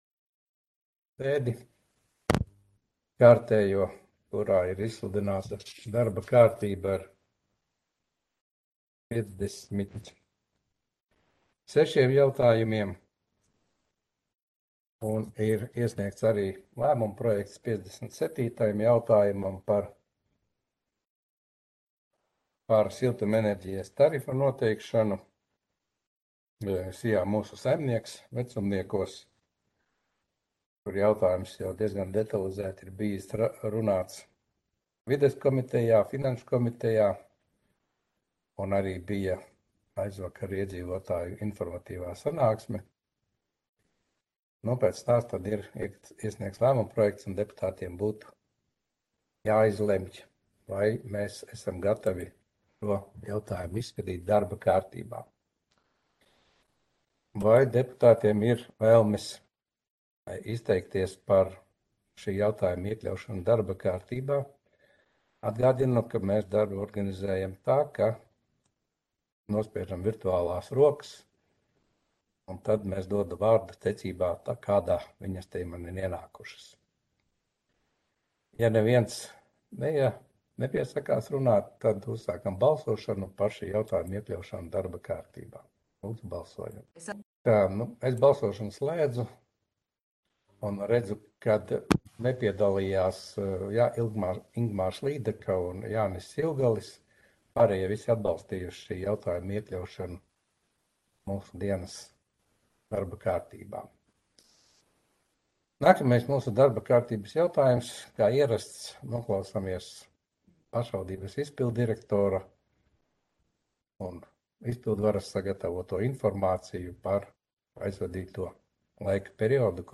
Audioieraksts - 2022.gada 27.janvāra domes sēde